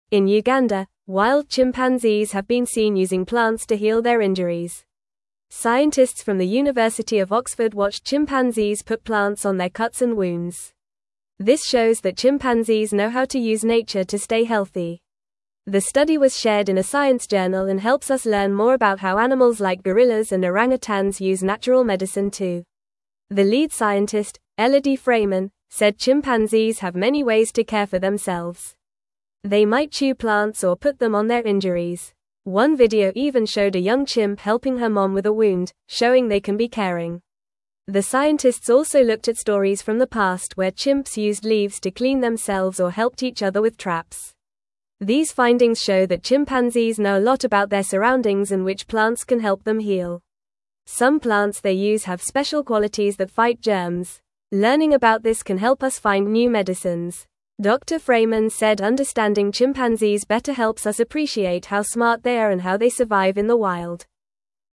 Fast
English-Newsroom-Lower-Intermediate-FAST-Reading-Chimps-Use-Plants-to-Heal-Their-Boo-Boos.mp3